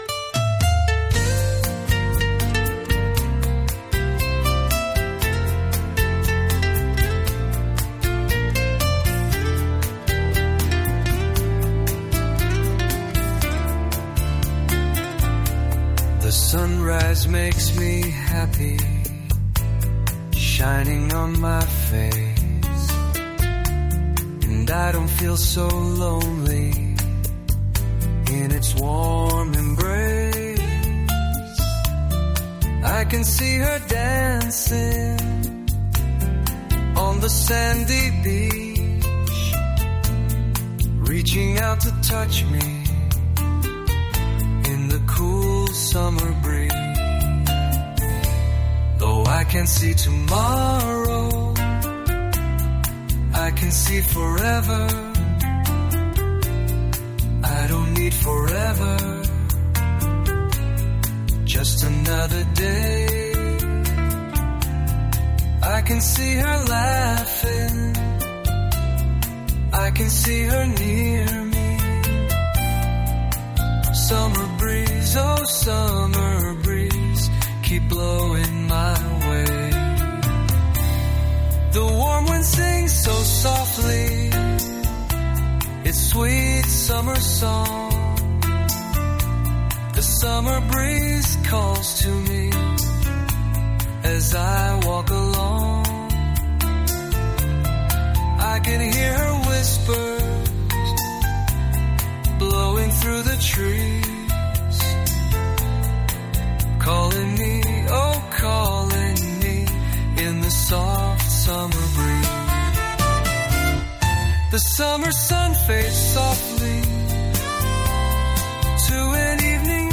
I took myself singing and playing it down and put up an AI vocal.